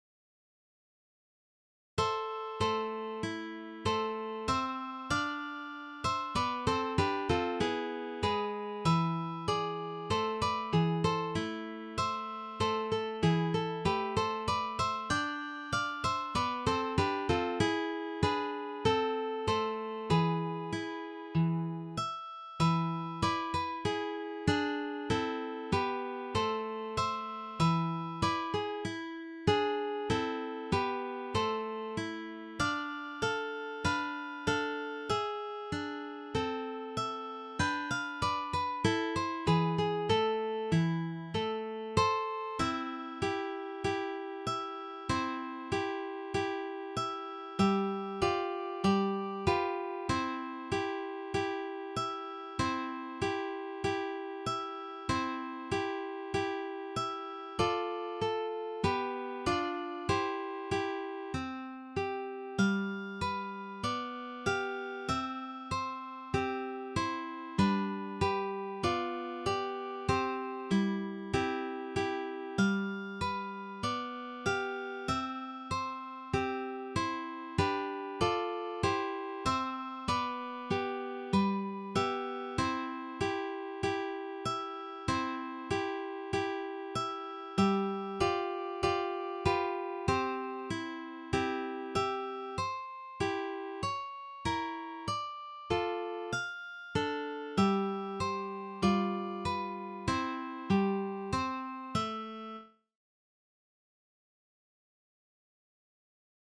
This is from the Classical period.